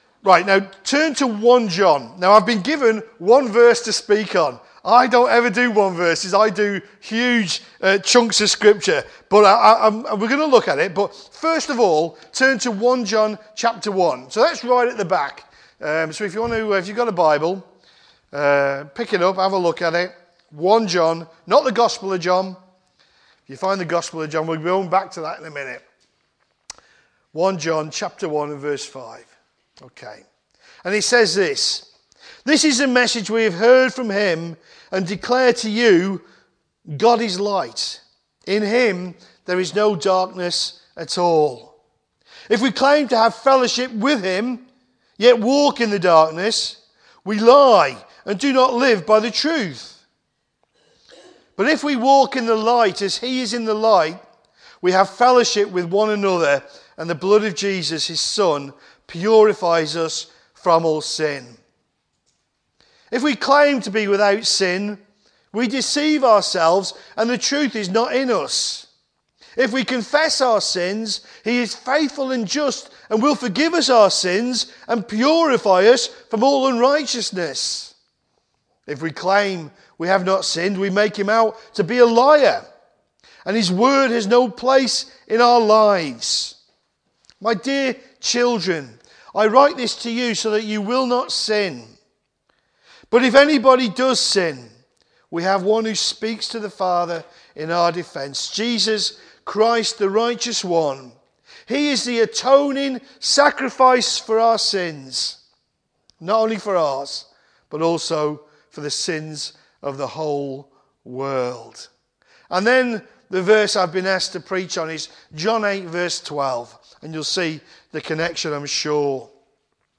Sunday AM Services